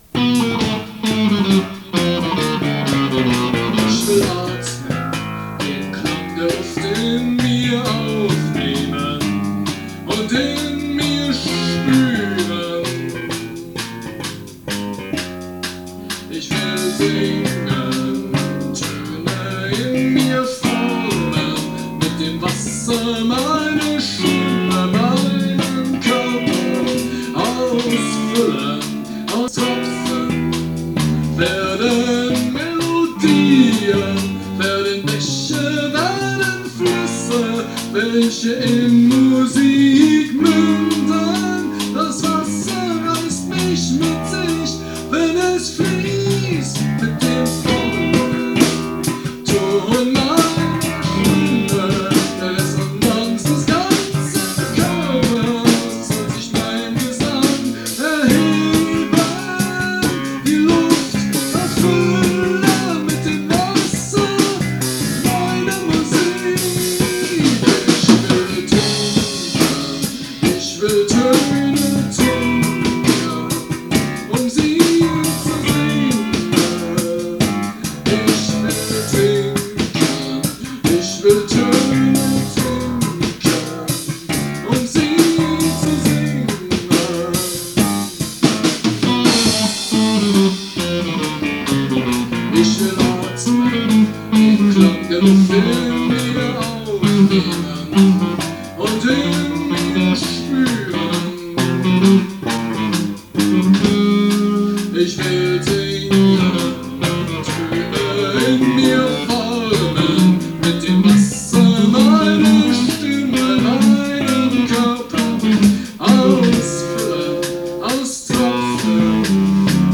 Münchner Progressive Rock – Band
Gesang / Bass
Schlagzeug
Saxophon / Klarinette
Gitarre